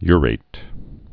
(yrāt)